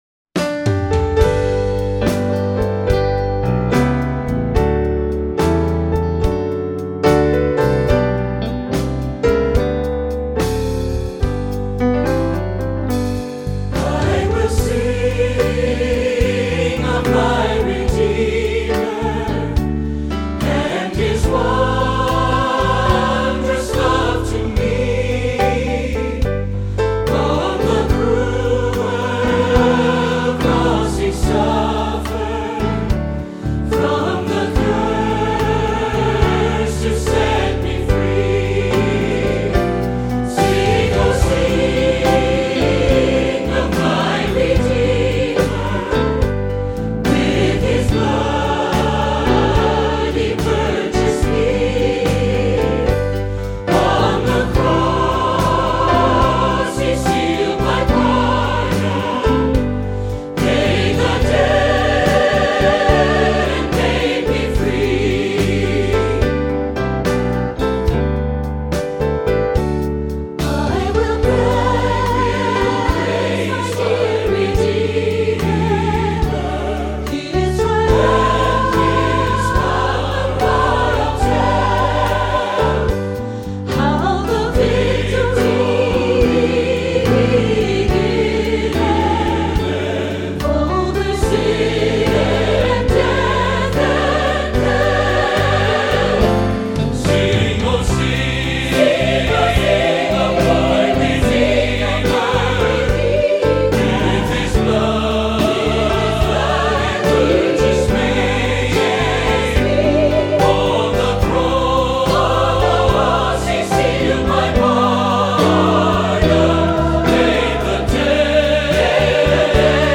Voicing: Instrumental Parts